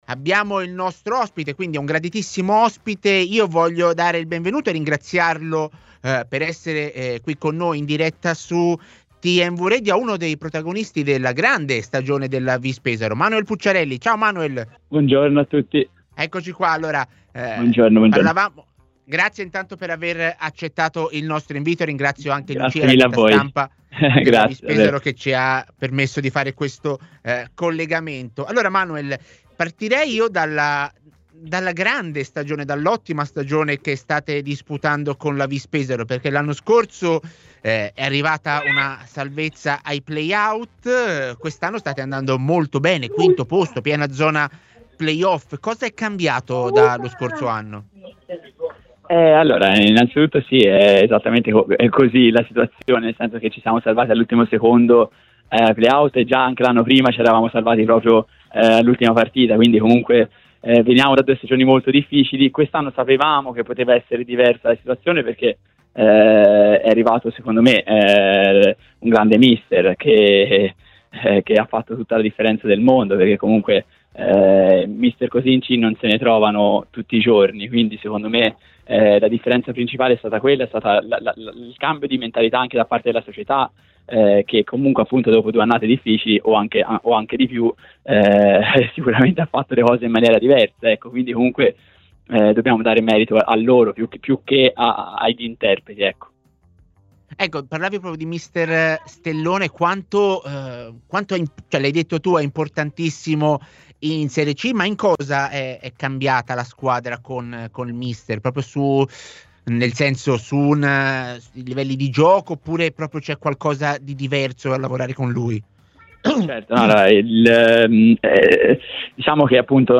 Ai microfoni di TMW Radio nel corso della trasmissione A Tutta C è intervenuto uno dei protagonisti della squadra guidata da Stellone